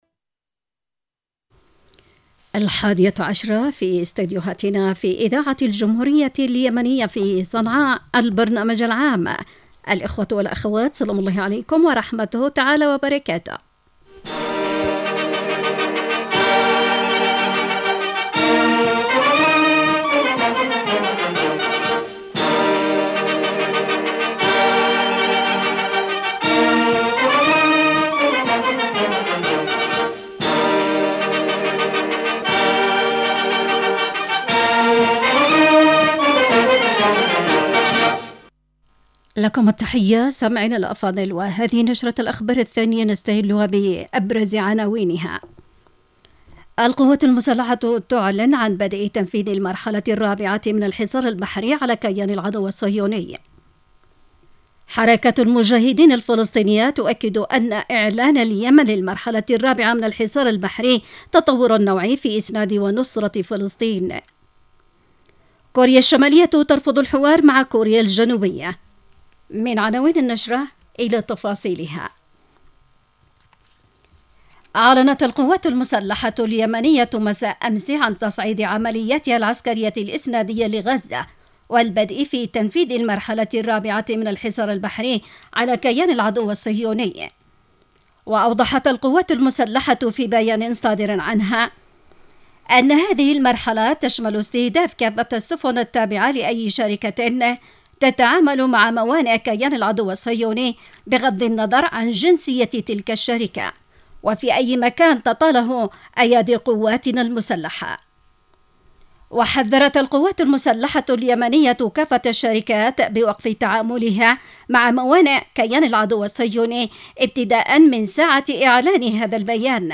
نشرة الحادية عشرة